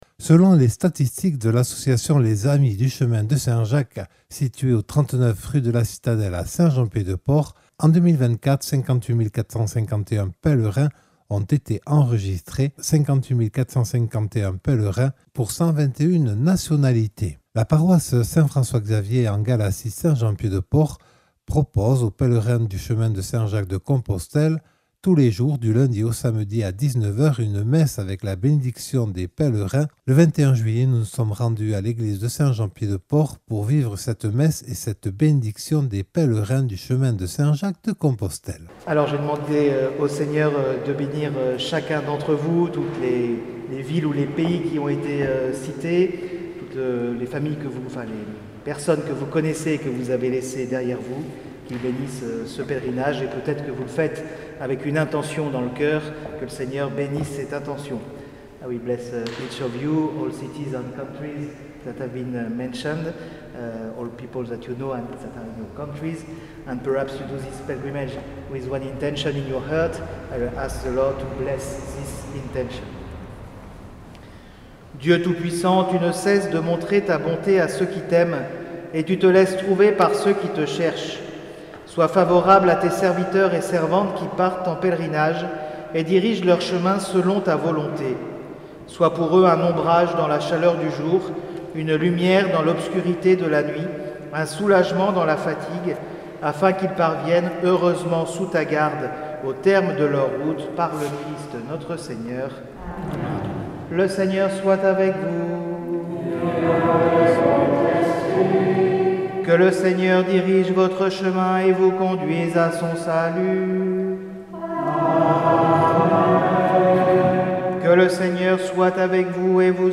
Tous les jours à 19h du lundi au samedi à l’issue de la messe a lieu la bénédiction des pèlerins : reportage réalisé le lundi 21 juillet 2025.